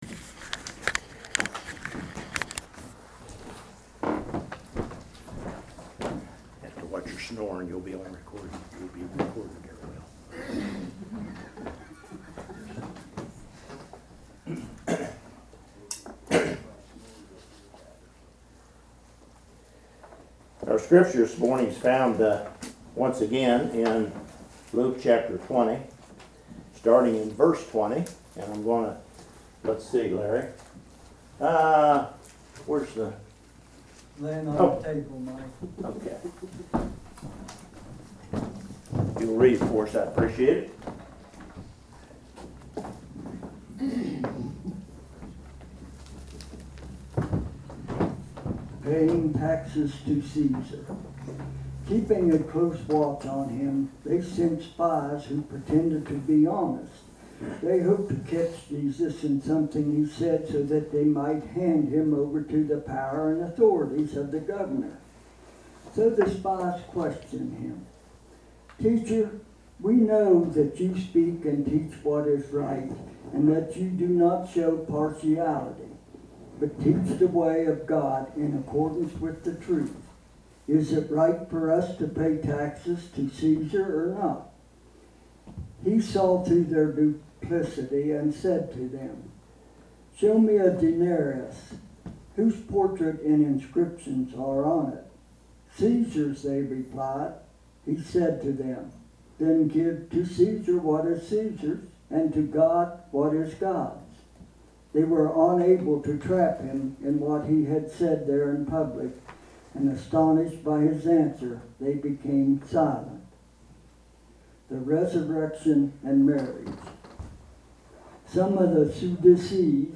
Luke Passage: Luke 20:20-40 Service Type: Worship Service Download Files Notes Topics